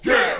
Vox
yeah.wav